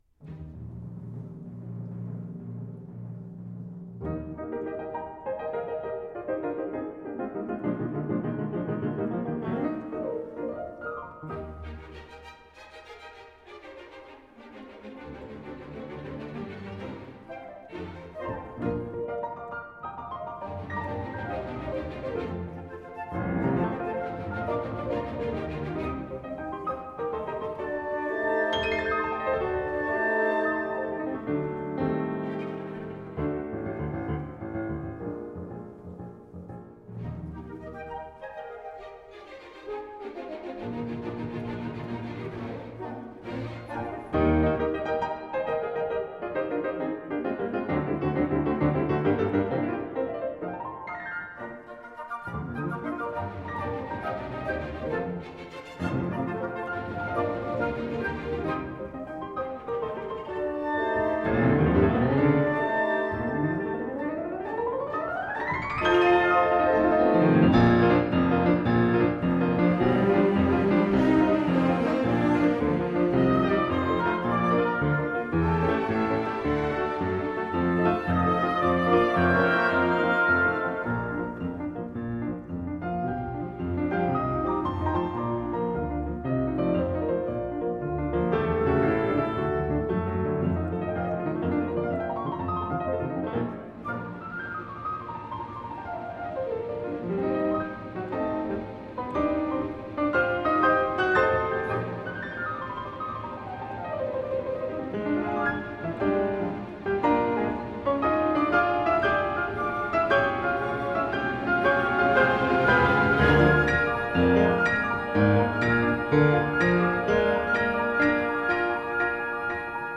Jean-Yvees Thibaudet, piano; L’Orchestre de la Suisse Romande. Charles Dutoit, dir.